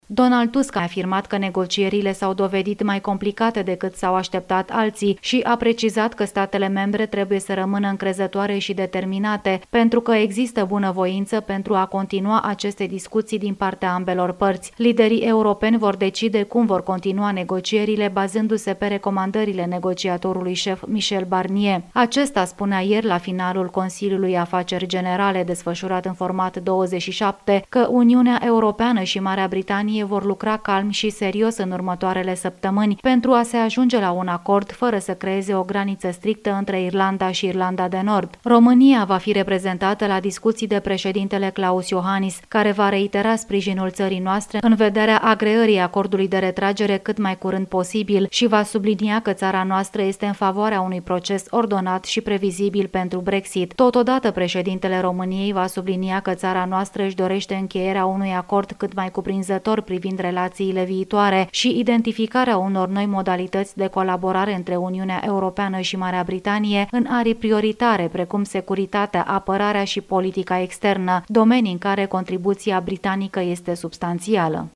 Potrivit corespondentului Radio România la Bruxelles, Tusk crede că scenariul unei separări fără acord este mai probabil ca oricând şi i-a îndemnat pe membrii Uniunii să fie pregătiţi pentru acestă variantă.